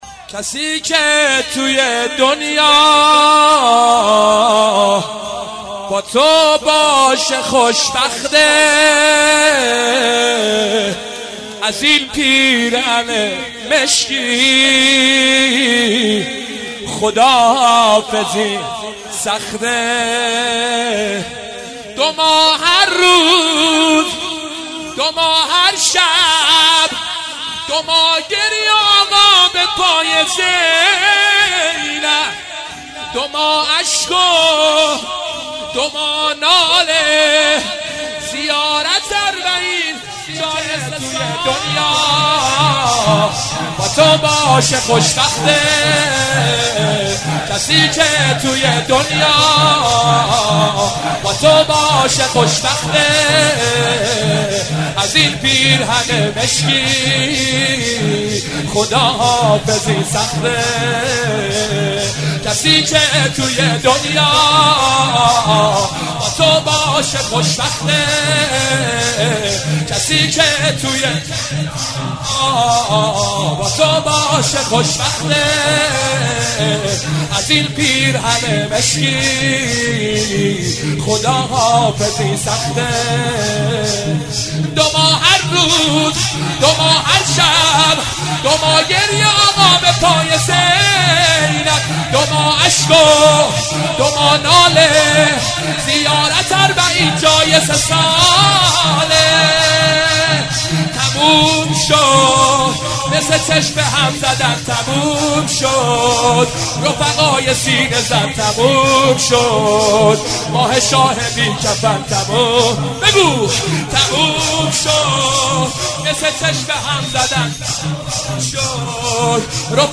یعنی استثنائی بود این مداحی